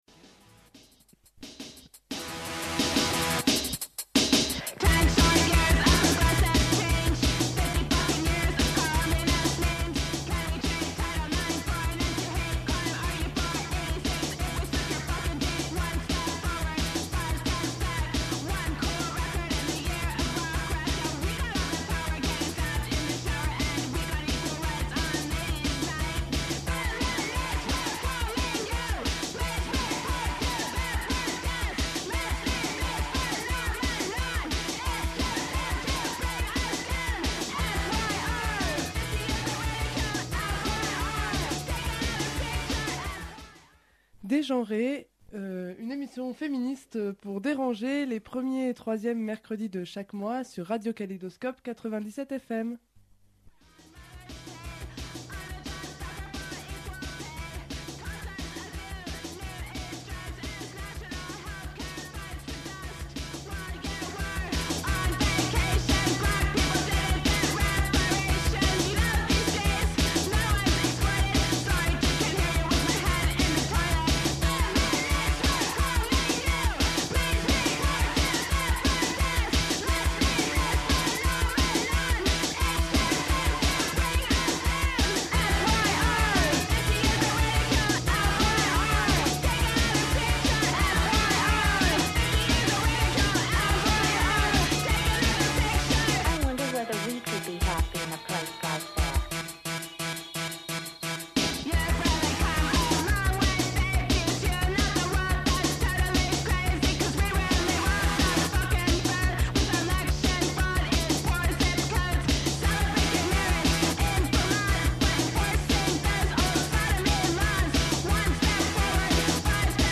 Interview par l'émission féministe Dégenrée de plusieurs militant-e-s de l'association Antigone, venant présenter les activités de cette bibliothèque, café, librairie et espace d'activités de Grenoble.
Cette émission a été diffusée sur Radio Kaléidoscope le 22 février 2006.